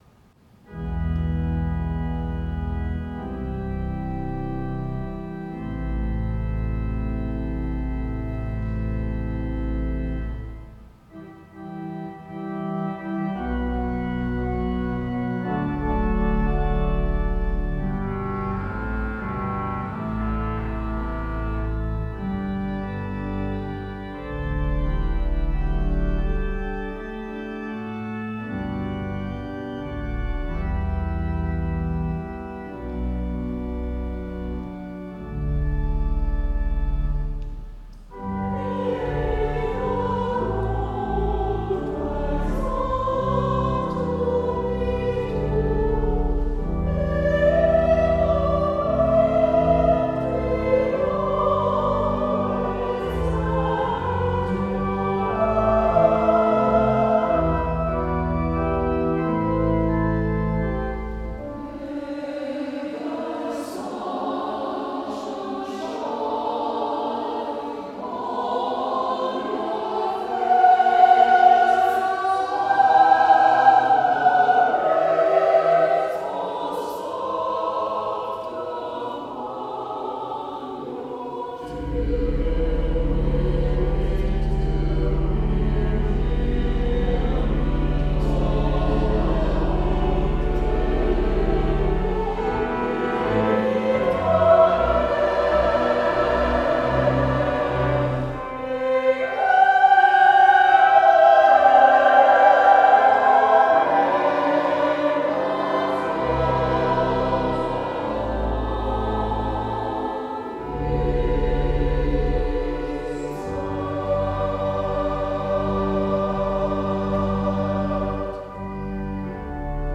Accompanied Anthems
IL for SSATB and organ and is setting of the traditional Irish text. � It is brief, arid and haunting.